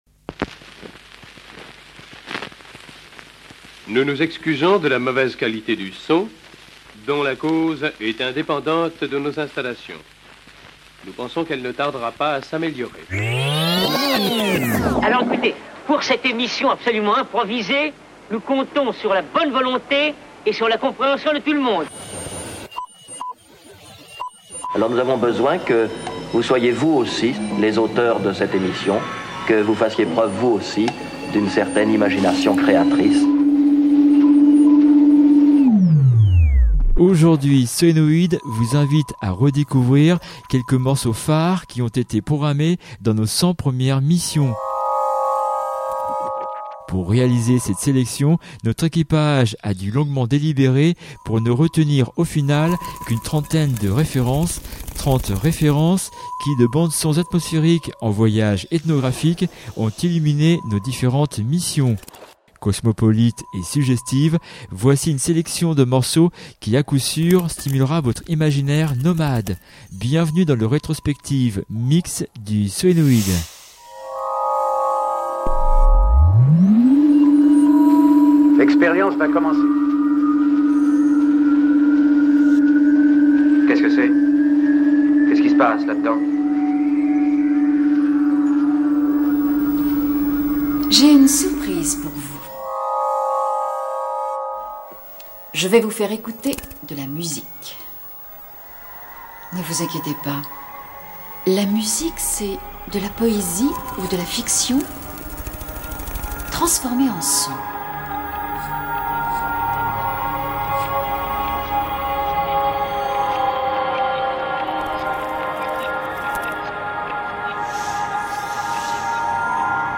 Cosmopolite et suggestive